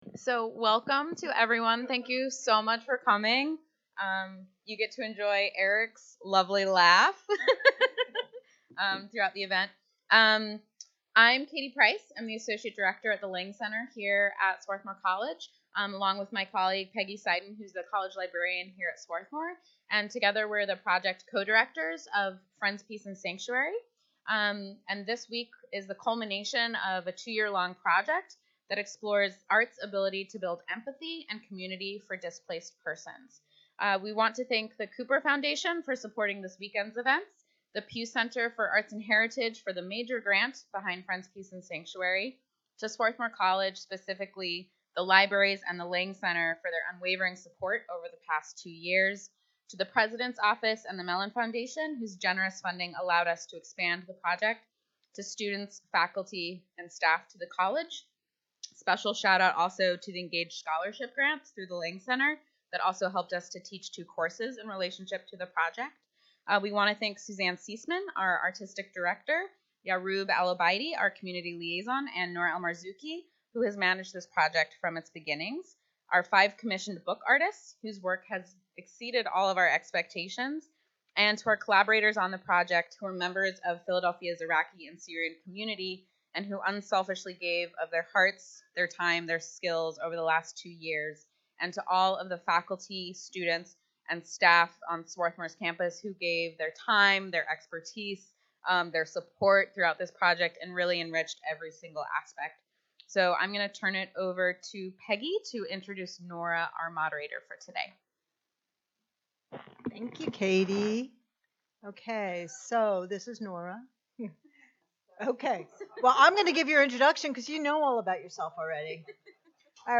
Art, History, Experience: A Panel on Displacement